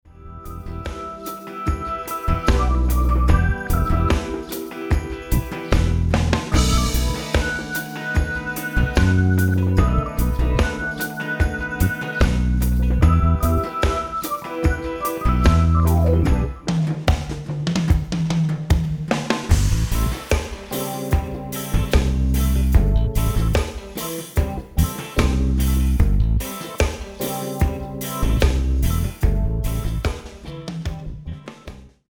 74 BPM